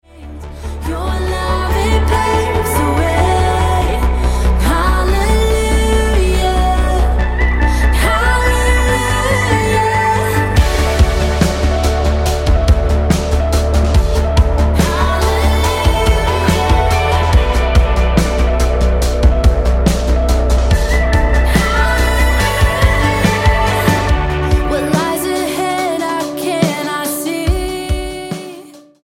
STYLE: Pop
The female led